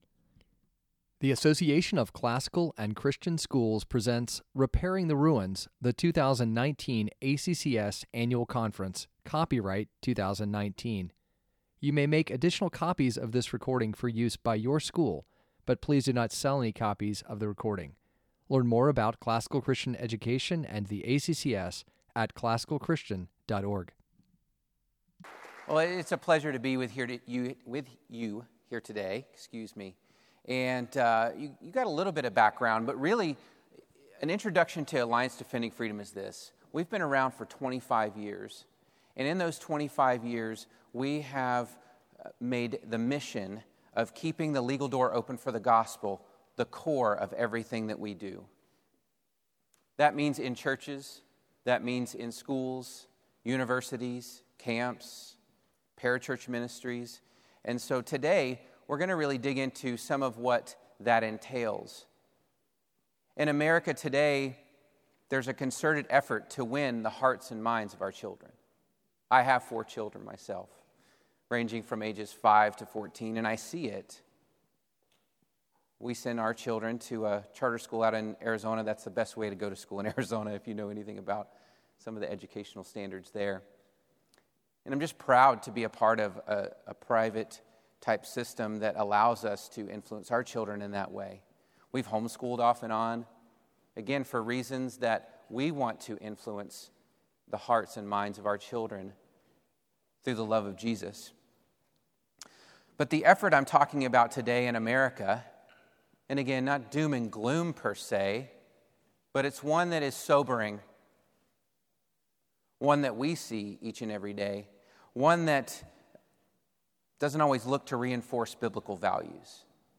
2019 Leaders Day Talk | 35:07 | Leadership & Strategic, Marketing & Growth